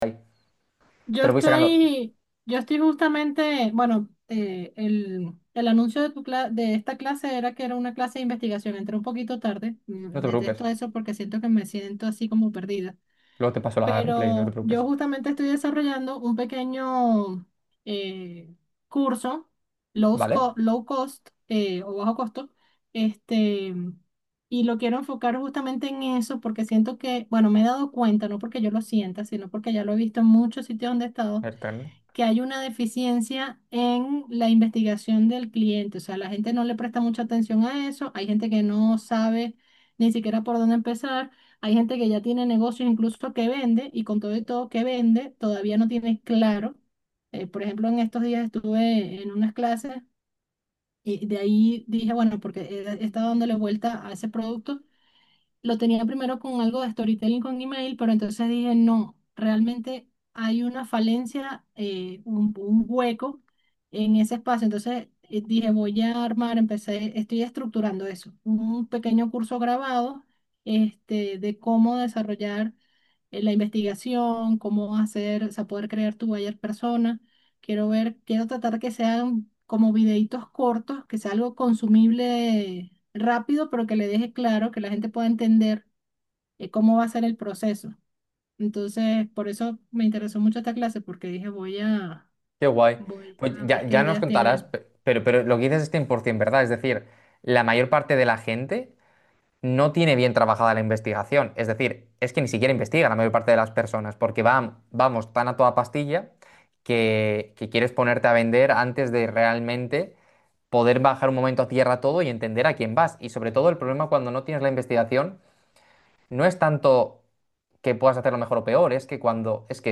¿Tienes dudas sobre cómo plantear una investigación profesional? Vemos en directo el paso a paso que puedes superar.